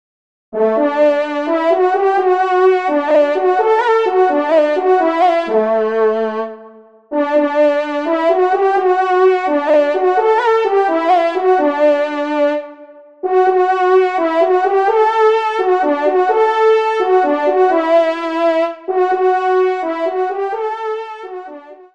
Fanfare de personnalité